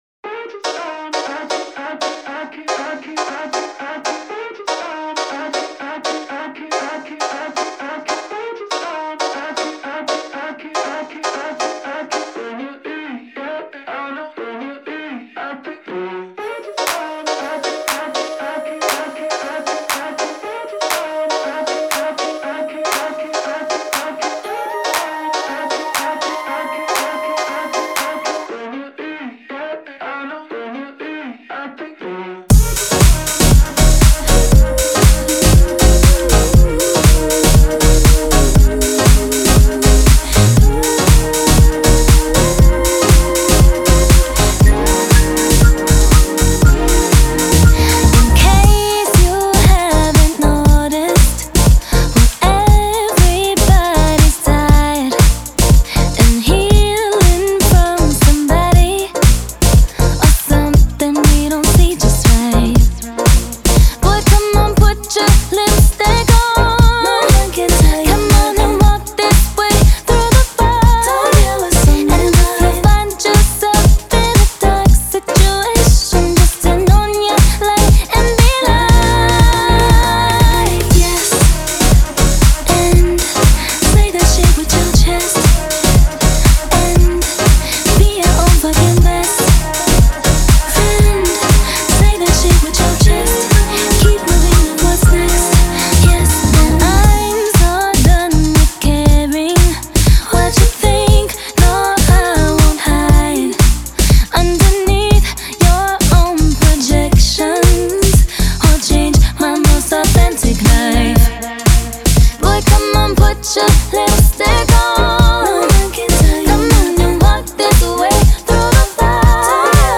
BPM119-119
Audio QualityPerfect (High Quality)
pop song for StepMania, ITGmania, Project Outfox
Full Length Song (not arcade length cut)